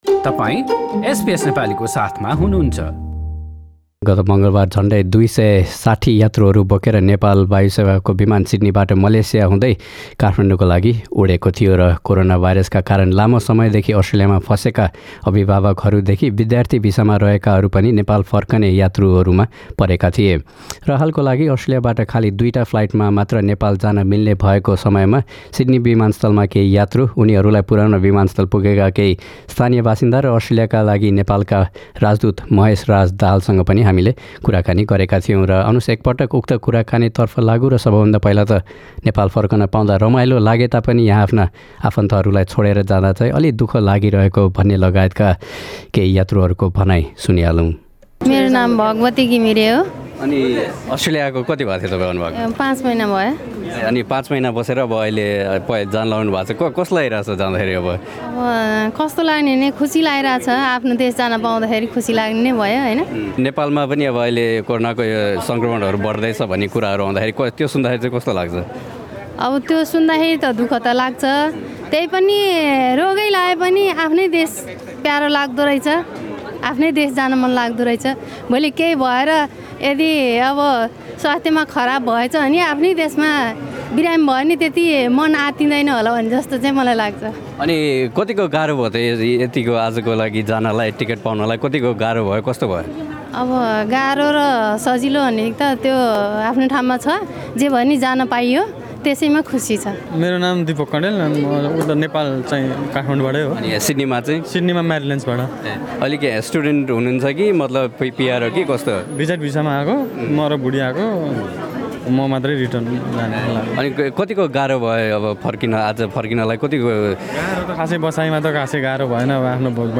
कोरोनाभाइरसका कारण लामो समयदेखि अस्ट्रेलियामा फसेका अभिभावकहरूदेखि विद्यार्थी भिसामा रहेकाहरू लगायतका झन्डै २६० यात्रुहरू बोकेर नेपाल वायुसेवा निगमको एक चार्टर विमान सिड्नीबाट मलेसिया हुँदै काठमाडौँको लागि गत मङ्गलवार उडेको थियो। हालको लागि अस्ट्रेलियाबाट नेपाल जान मिल्ने केवल दुई वटा उडान मात्र भएको बताइँदै गर्दा सिड्नी स्थित किङ्ग्सफोर्ड स्मिथ विमानस्थलमा जम्मा भएका केही यात्रु, उनीहरूलाई पुराउन विमानस्थल पुगेका परिवारजन र अस्ट्रेलियाका लागि नेपालका राजदूत महेशराज दाहालसँग हामीले गरेको कुराकानी।